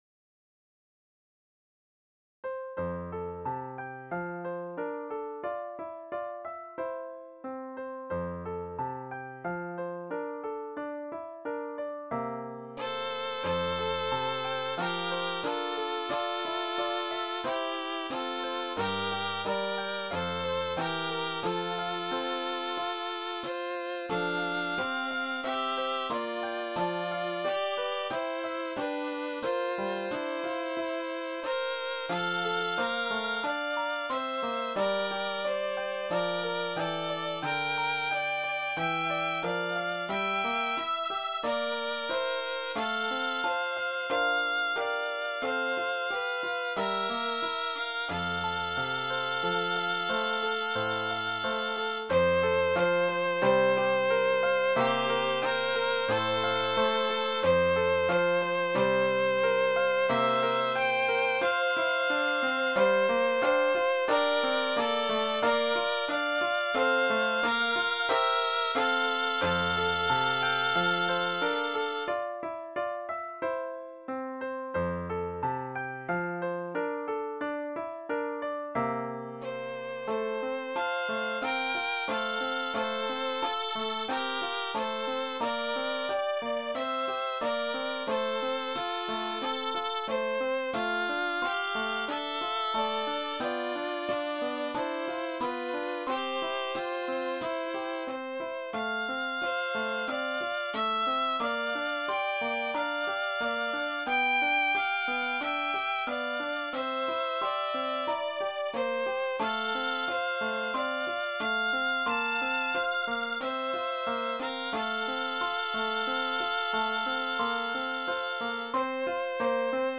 Cello Duet/Cello Ensemble Member(s), Violin Duet/Violin Ensemble Member(s)